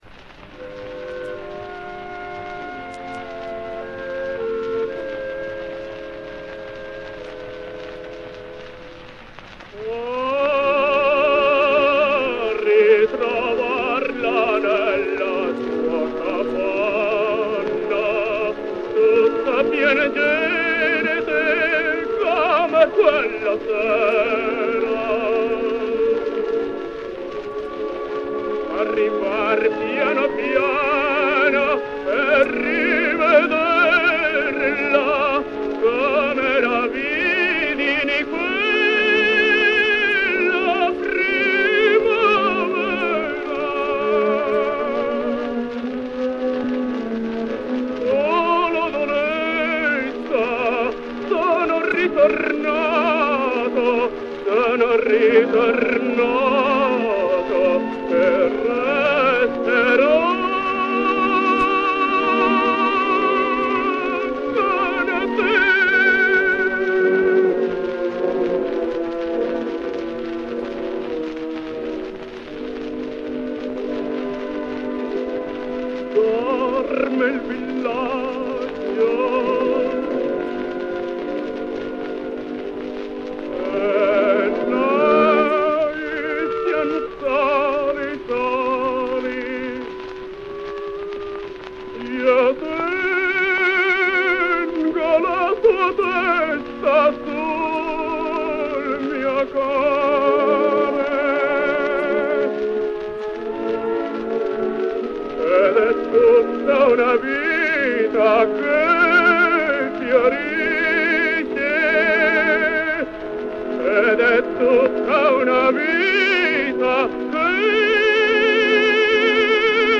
Franco Lo Giudice sings Lodoletta: